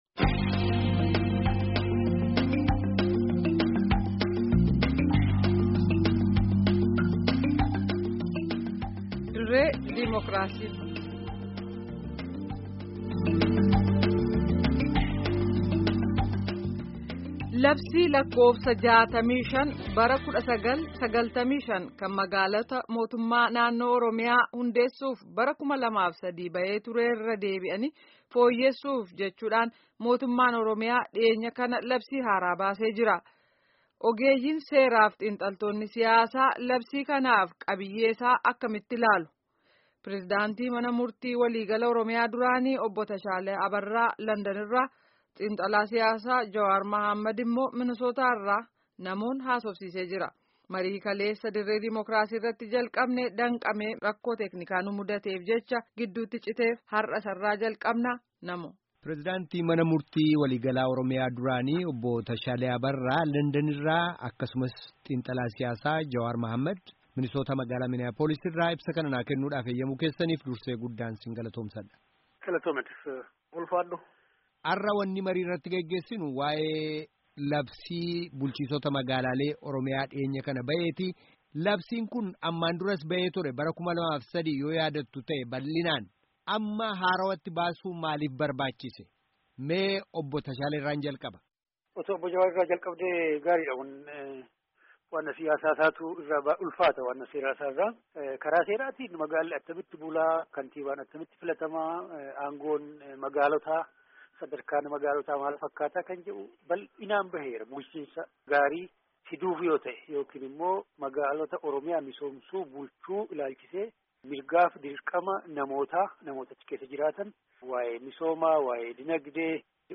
Gaaffii fi deebii geggeessame kutaa 1ffaa dhaga'aa